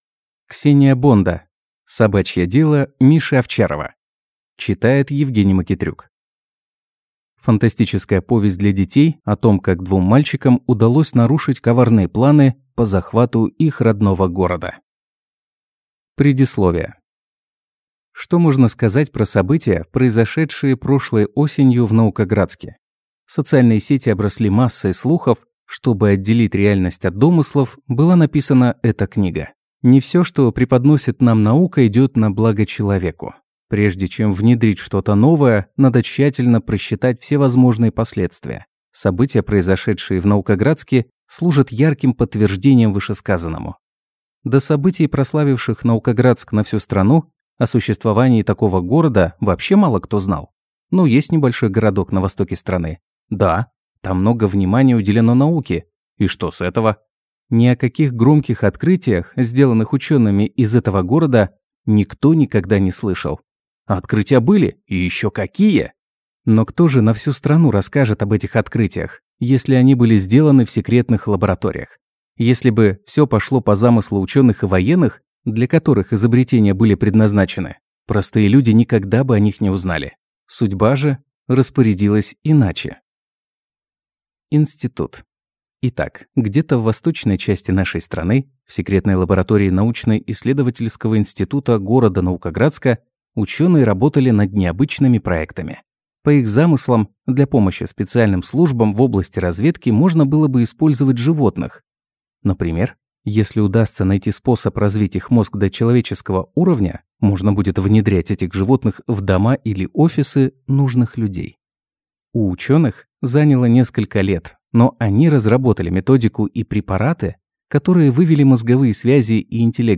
Аудиокнига «Новые русские люди». Автор - Михаил Салтыков-Щедрин.